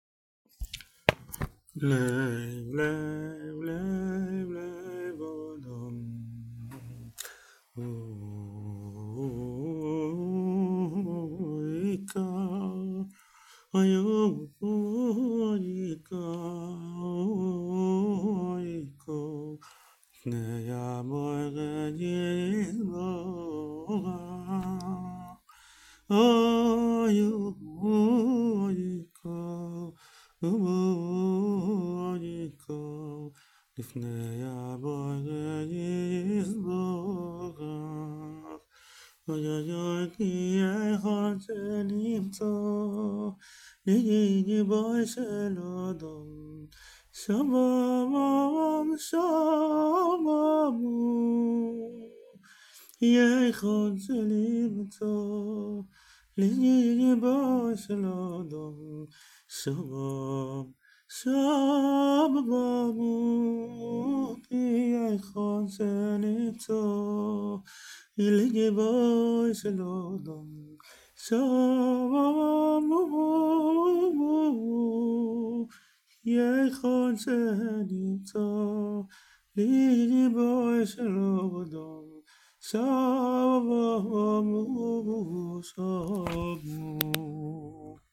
מאוד לא ברור הקלטה שקטה מאוד…